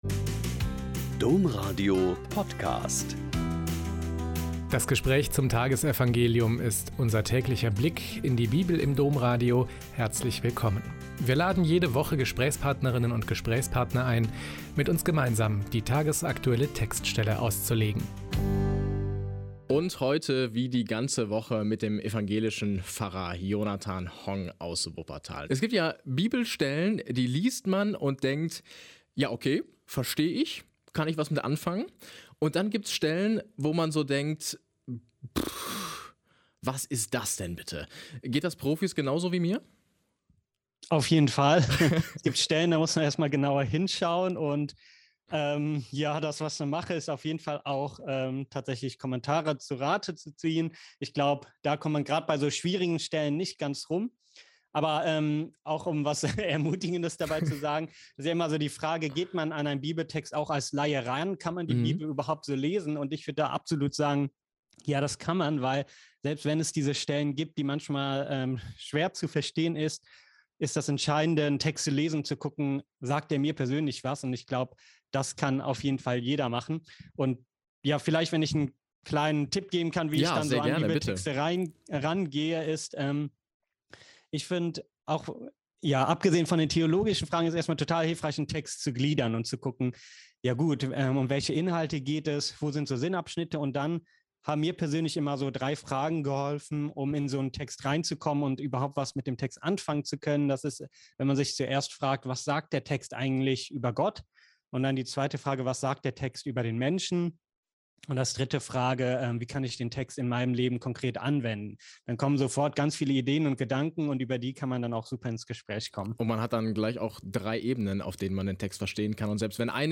Mk 9,2-13 – Gespräch